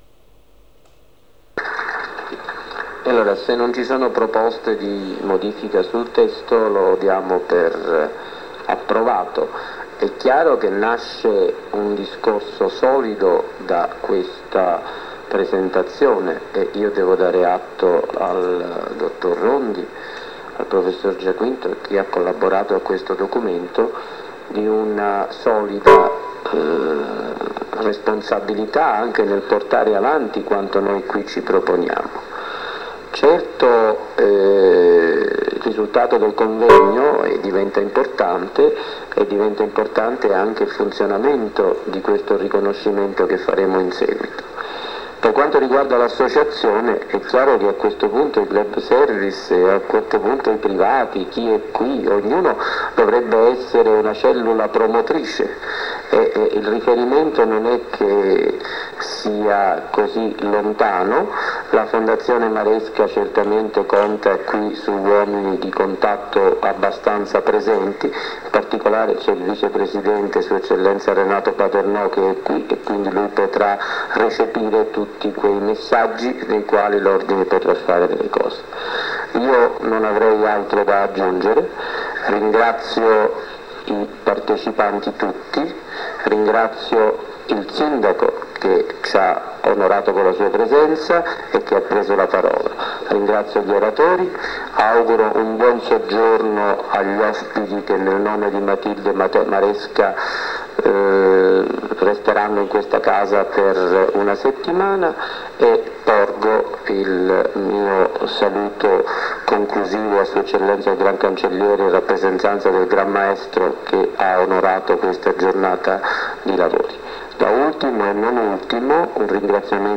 DISCORSI - CONFERENZE - INTERVISTE
• CONVEGNO INTERNAZIONALE FONDAZIONE MATILDE MARESCA SORRENTO 22/23 DICEMBRE